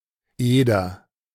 The Eder (German pronunciation: [ˈeːdɐ]
De-Eder.ogg.mp3